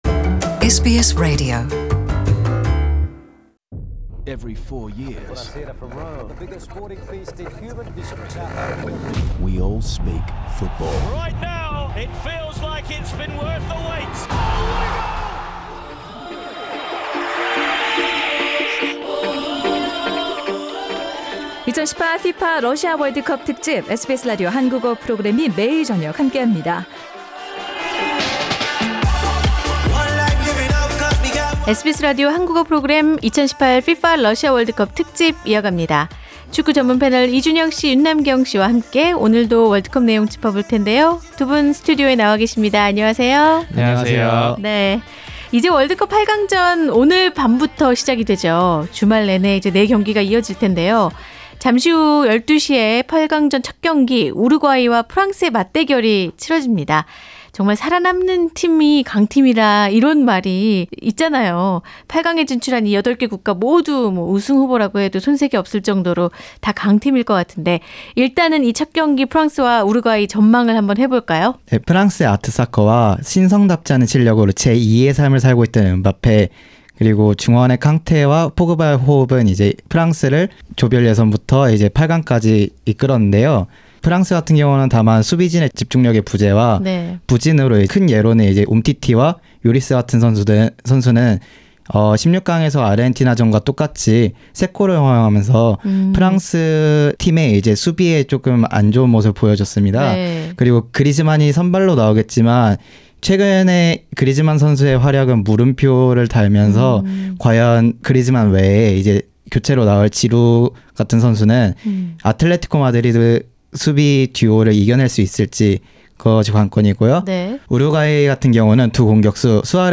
Two commentators in Sydney go over the interesting points you could focus on as we watch the quarterfinal games .